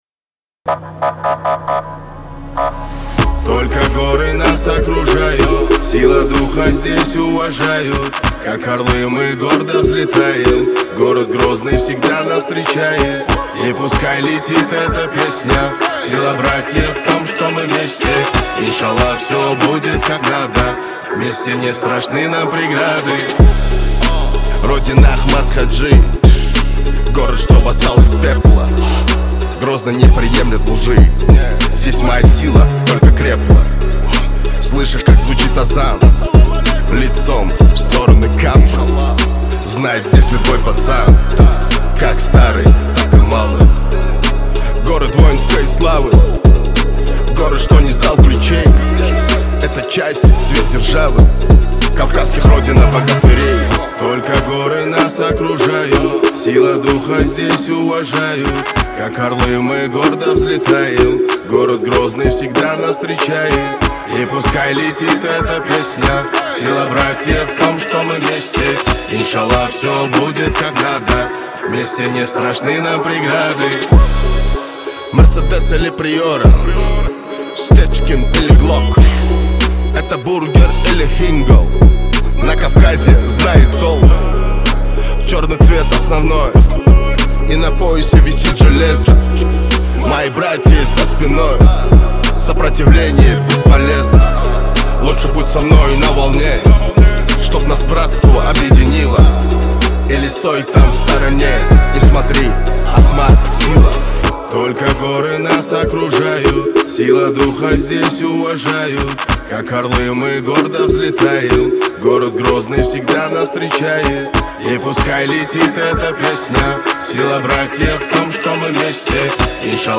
это мощный трек в жанре рэп